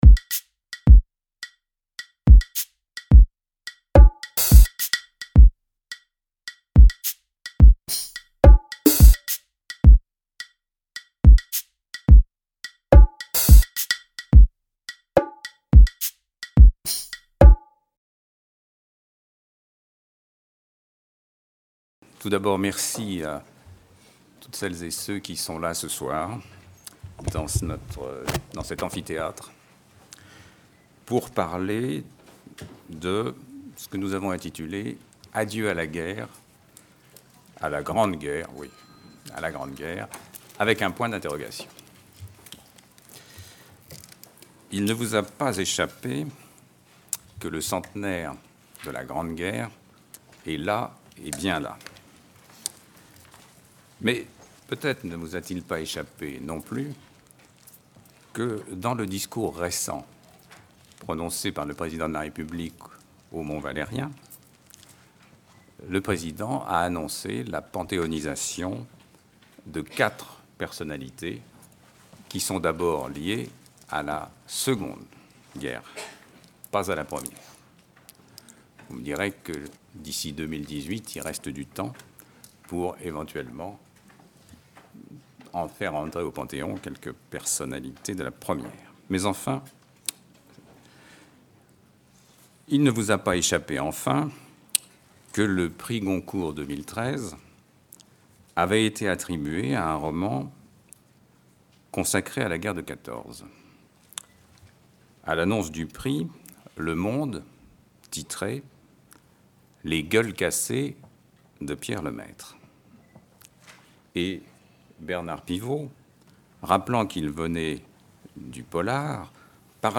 Débat organisé par les Éditions de l'EHESS et les Cercles de formation de l'EHESS Débat autour du livre de Stéphane Audoin-Rouzeau Quelle histoire ? Un récit de filiation (1914-2014). Ce livre est le fruit d’une expérience historiographique.